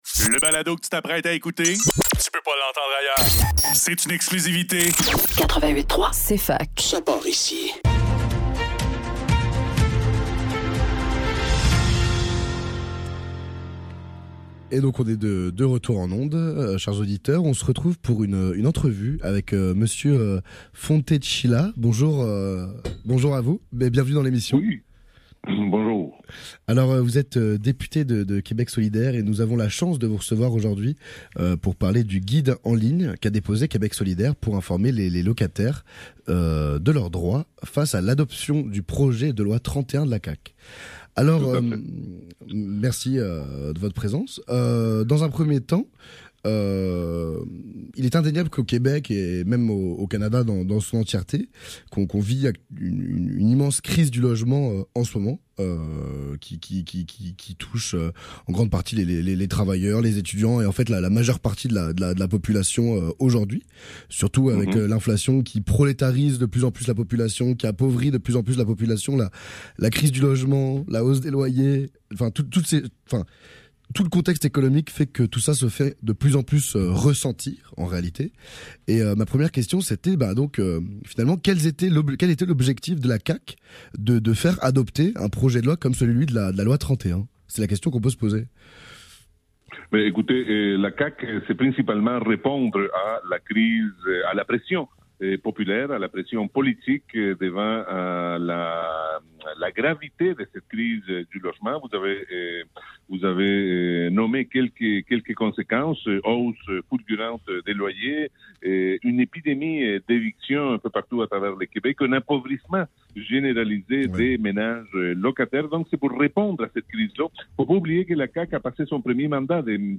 Le NEUF - Entrevue avec Andrés Fontecilla - 3 avril 2024